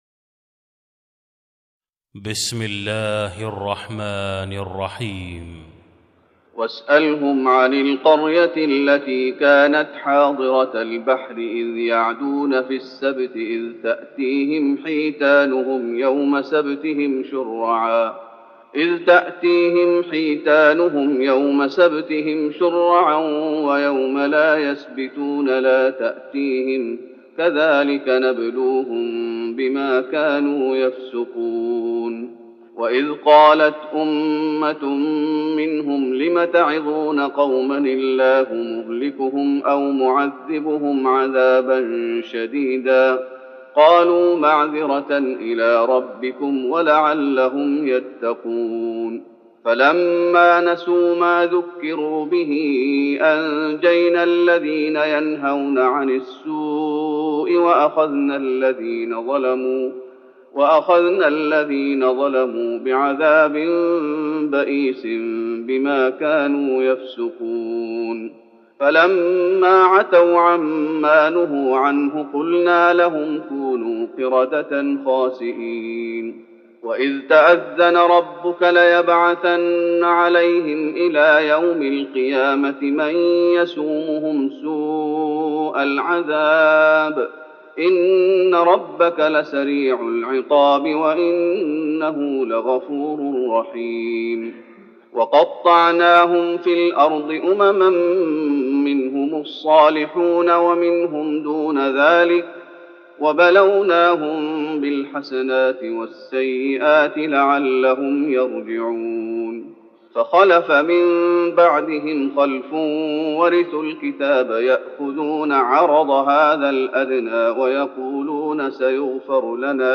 تراويح رمضان 1413هـ من سورة الأعراف (163-206) Taraweeh Ramadan 1413H from Surah Al-A’raf > تراويح الشيخ محمد أيوب بالنبوي 1413 🕌 > التراويح - تلاوات الحرمين